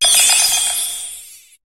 Cri d'Hexagel dans Pokémon HOME.